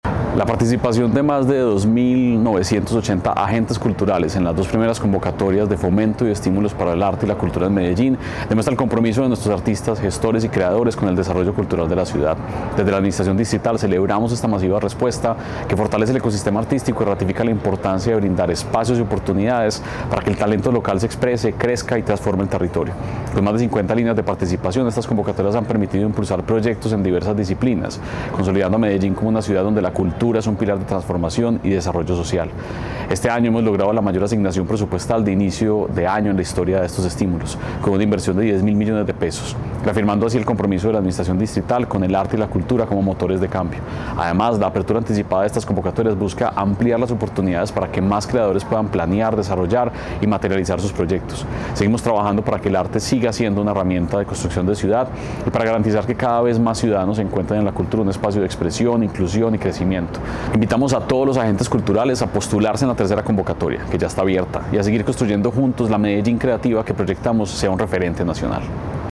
Audio Palabras de Santiago Silva, secretario de Cultura Ciudadana